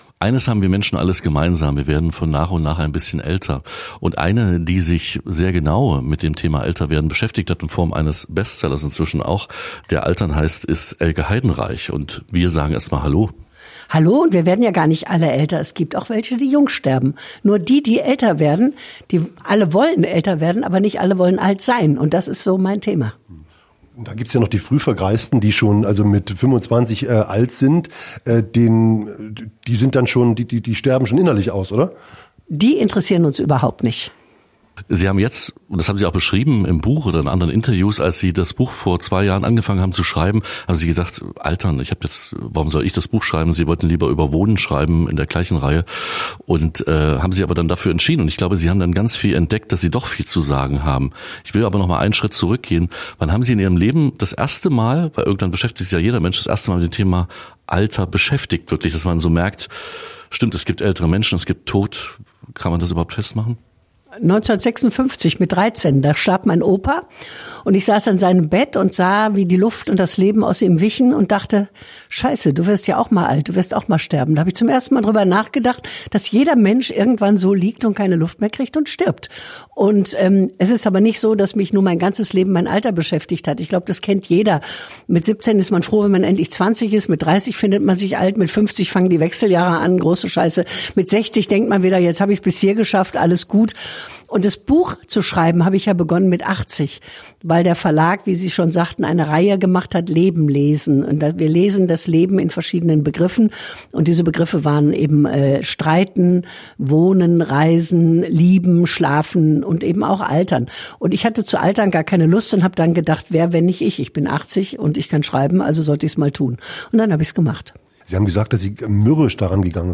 Elke Heidenreich - Altern_Interview_2025.mp3